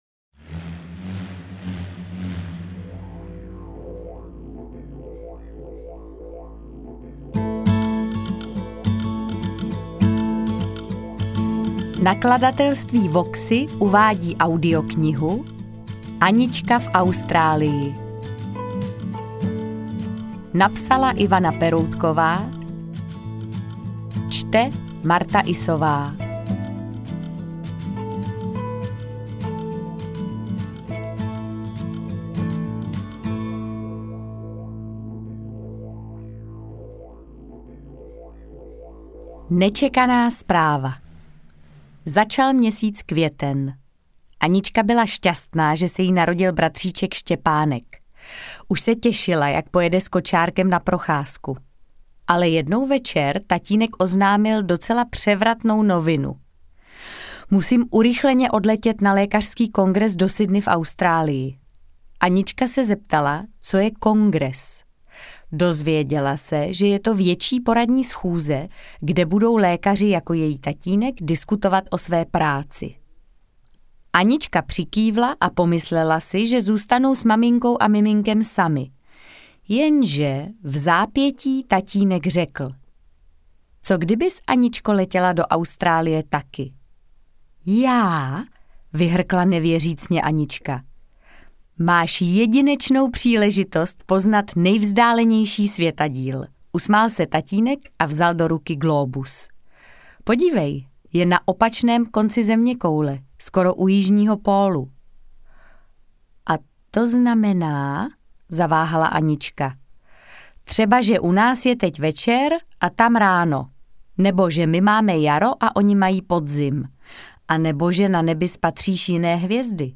Interpret:  Martha Issová